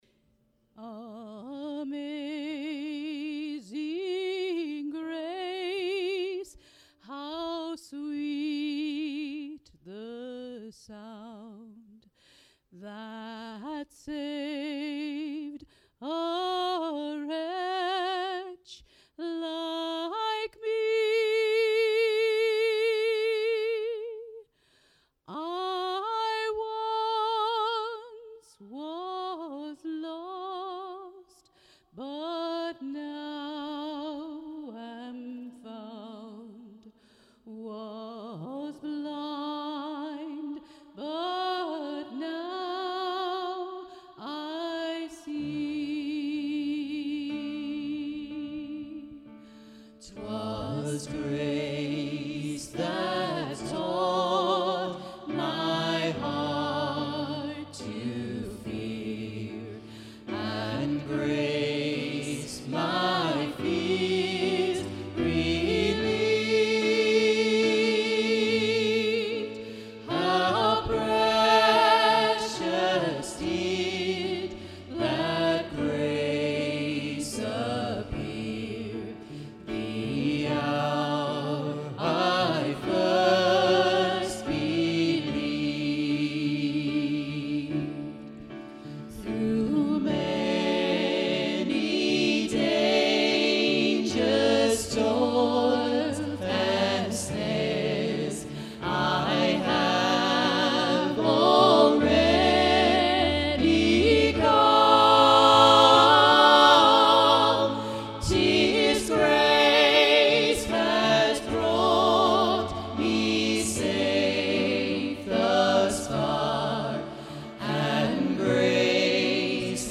A collection of traditional Hymns